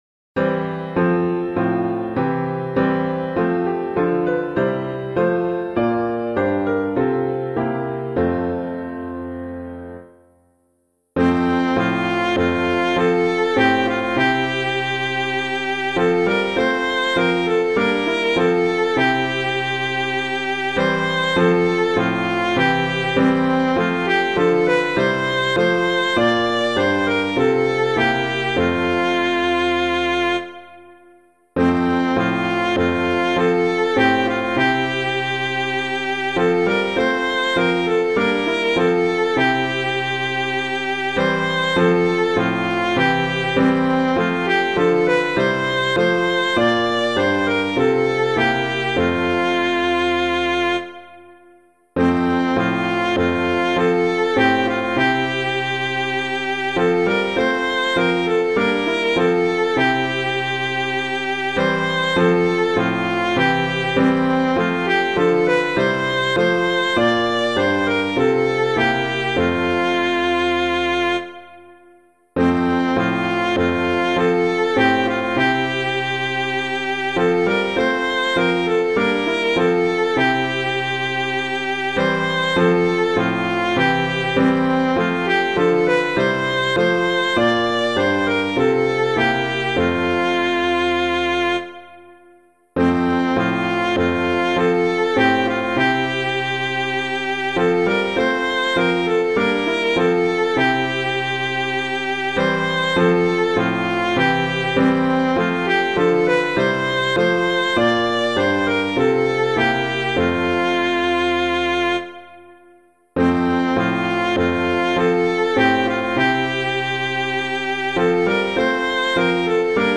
piano
The Coming of Our God [Campbell - SAINT THOMAS] - piano.mp3